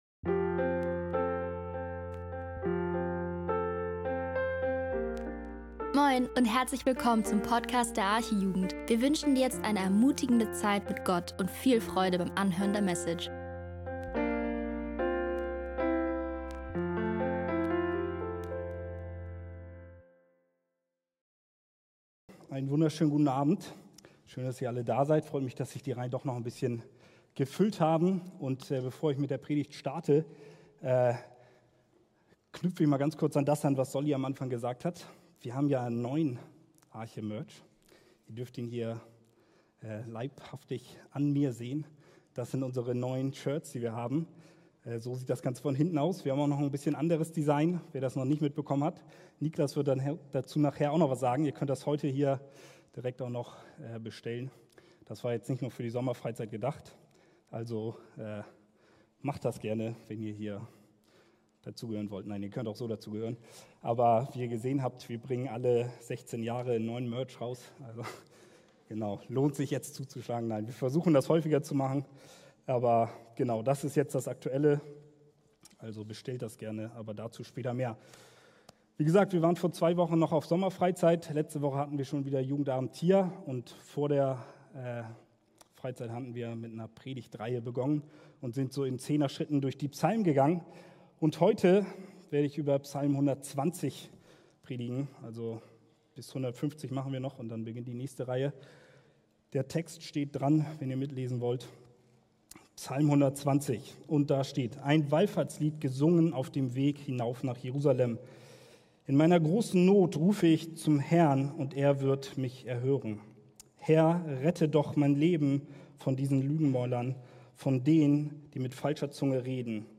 Dass dieFreude nur kurz anhält – und dann bleibt wieder diese Leere zurück?In dieser Predigt geht es genau darum: Was ist echte Freude – und wie unterscheidet siesich von oberflächlicher Kurzzeitfreude?Wir schau…